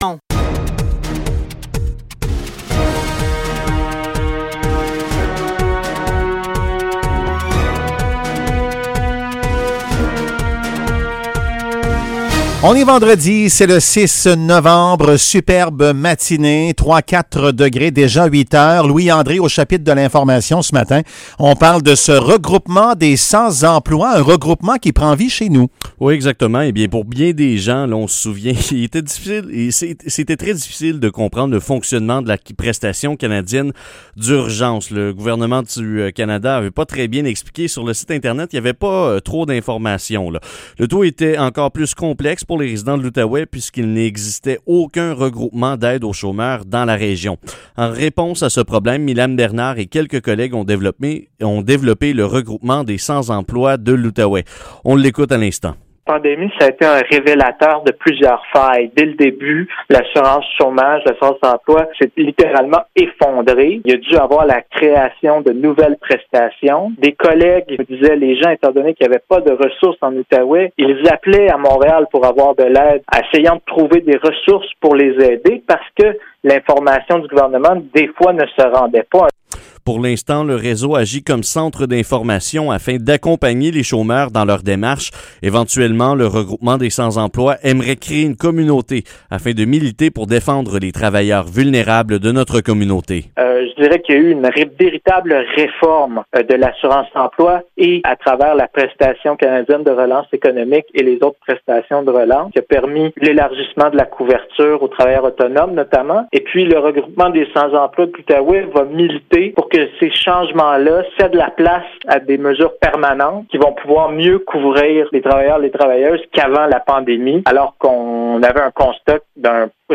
Épisode Nouvelles locales - 6 novembre 2020 - 8 h